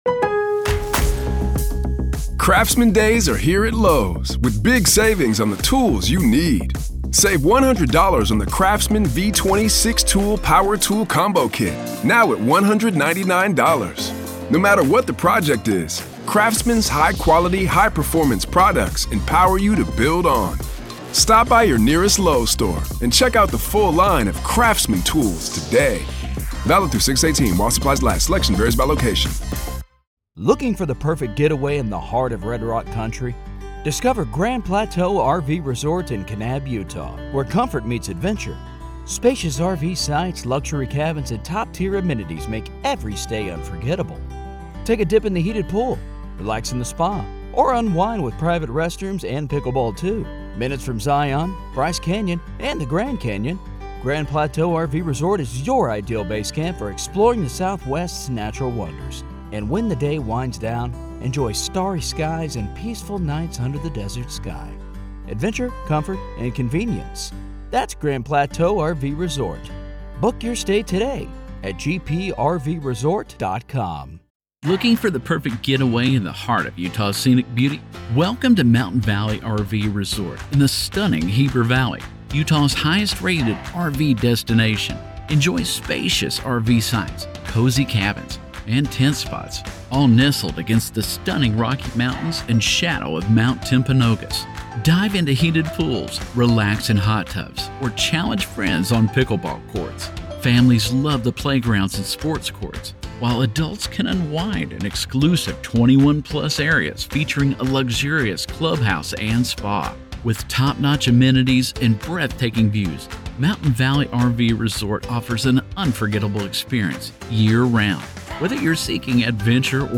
This is audio from the courtroom in the high-profile murder conspiracy trial of Lori Vallow Daybell in Arizona.